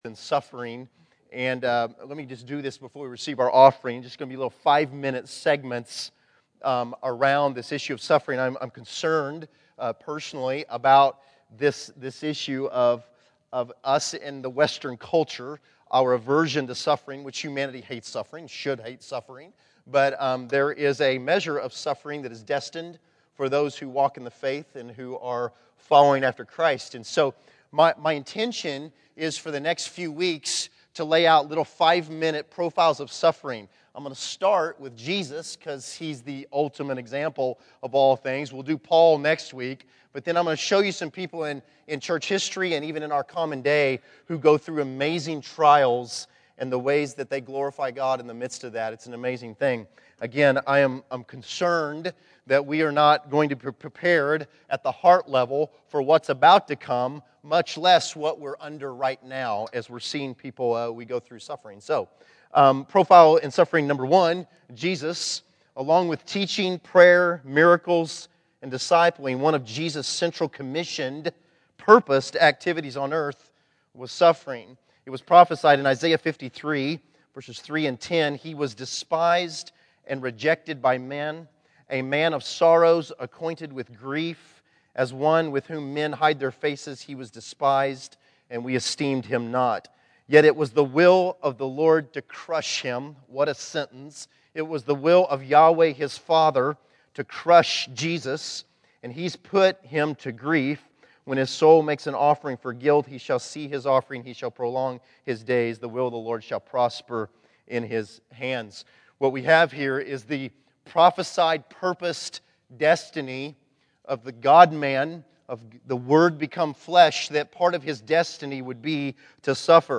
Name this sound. Category: Scripture Teachings